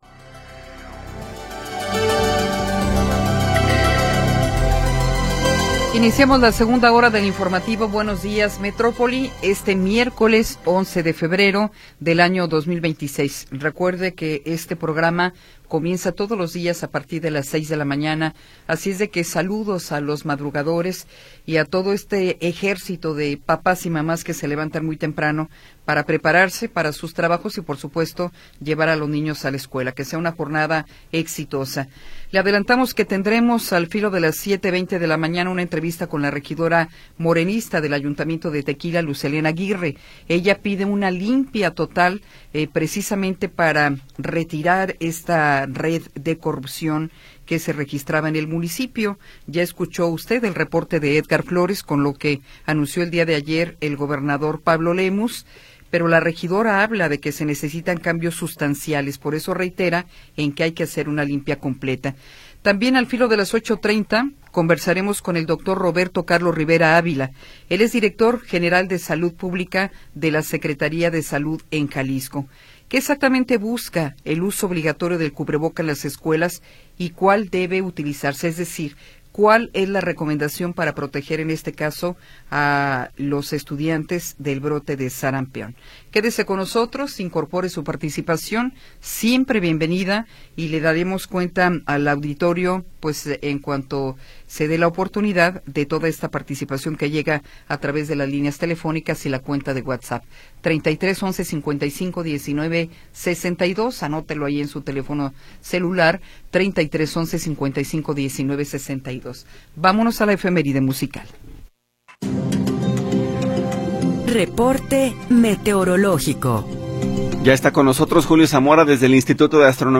Segunda hora del programa transmitido el 11 de Febrero de 2026.